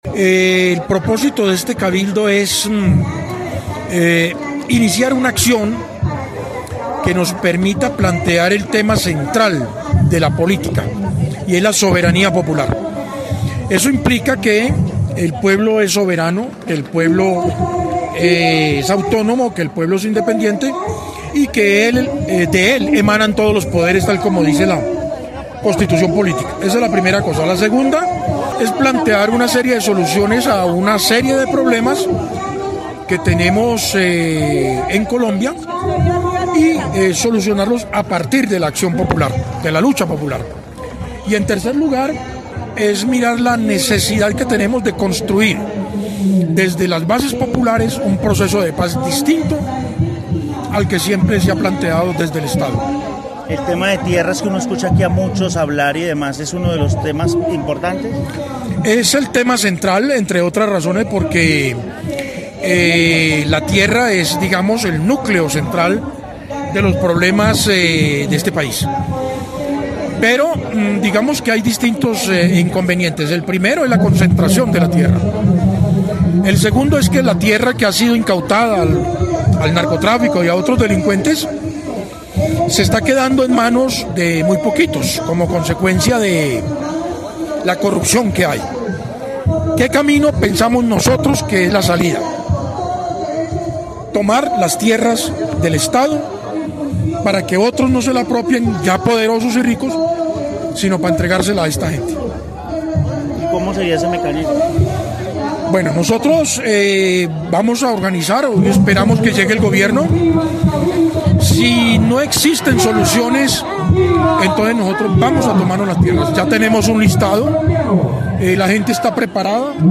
Cabildo Popular Nacional en la plaza de Bolívar de Armenia